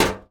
metal_tin_impacts_movement_rattle_06.wav